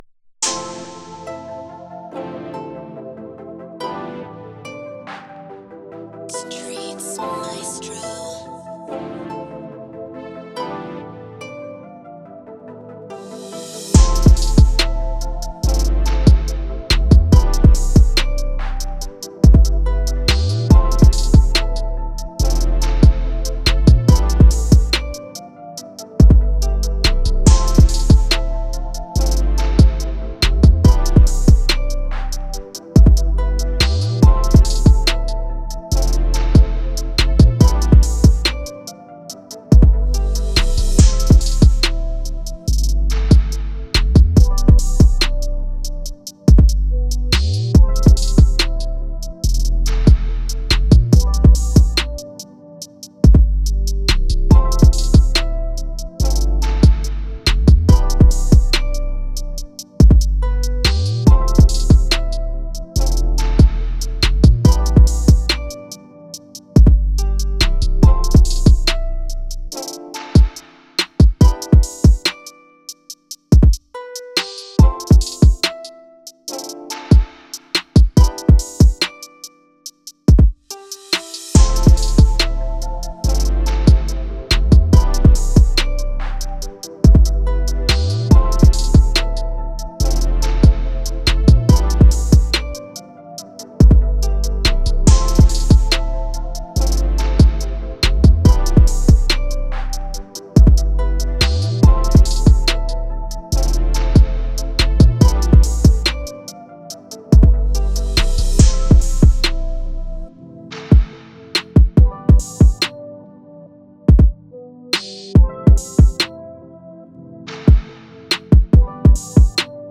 Laid Back Beat
Moods: Laid back, smooth, intimate
Genre: R&B/Drill
Tempo: 142
is a laid back, smooth, intimate beat.